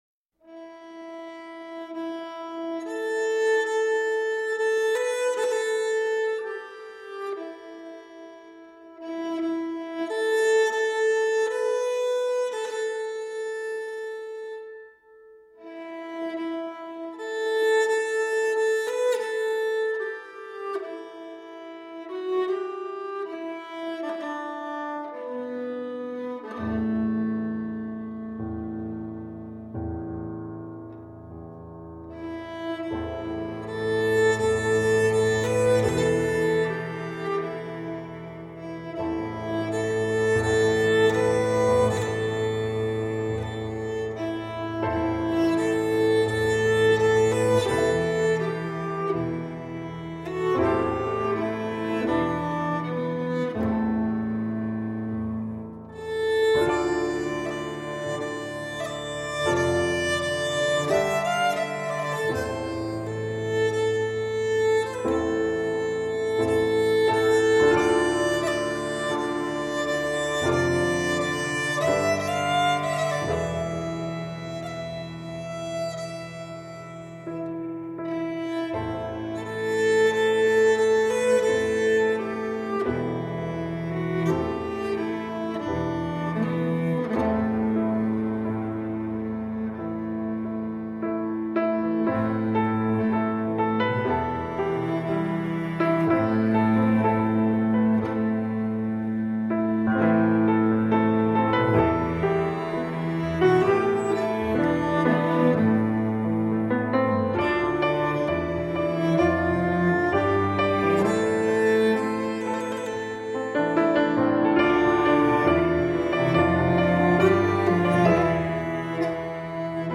New age meets indian cello.
Tagged as: World, Folk, World Influenced